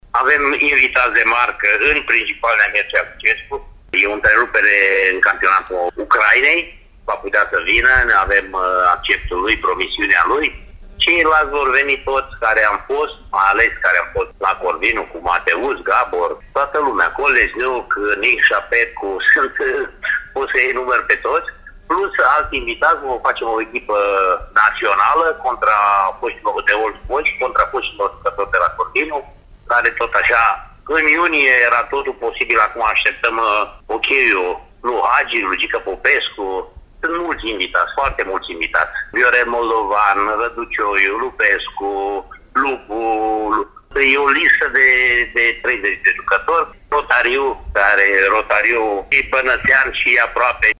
Fostul jucător al Corvinului Ioan Andone se află printre organizatori și spune că la festivitățile din septembrie vor exista și invitați speciali dintre fotbaliștii care nu au evoluat pentru Corvinul, dar înseamnă mult pentru fotbalul românesc, precum Gheorghe Hagi, Gheorghe Popescu sau Iosif Rotariu: